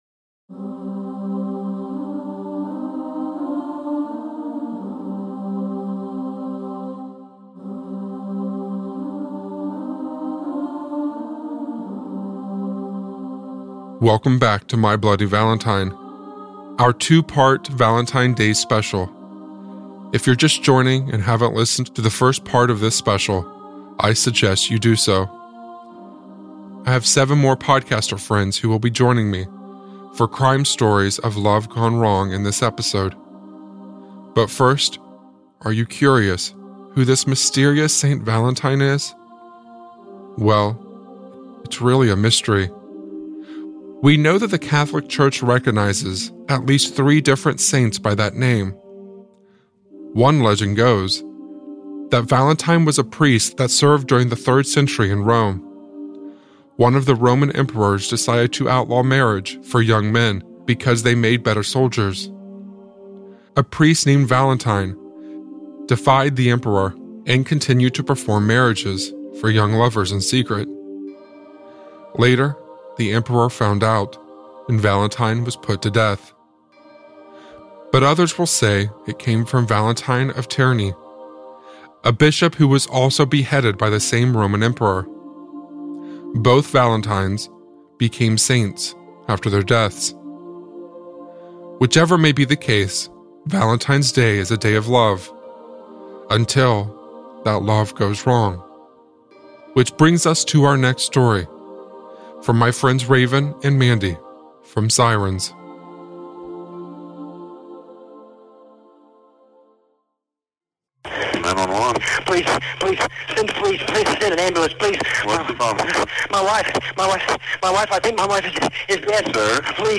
Each host brings a new, love gone wrong true story to mix.